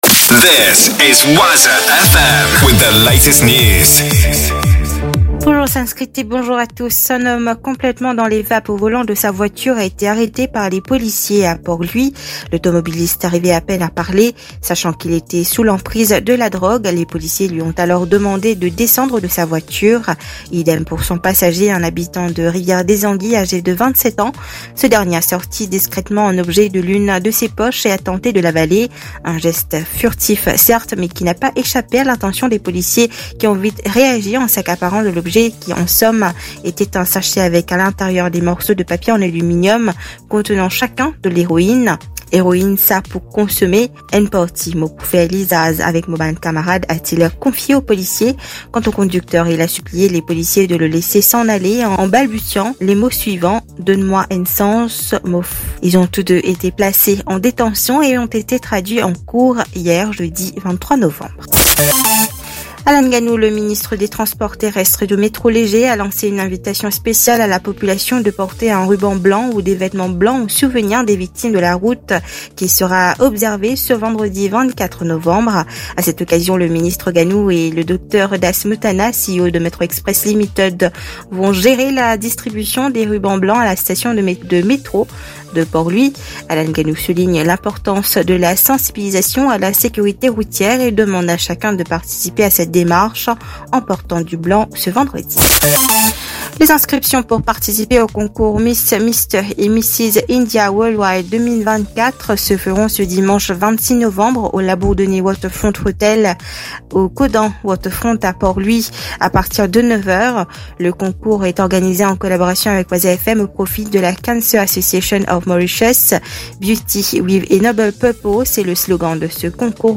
NEWS 7H - 24.11.23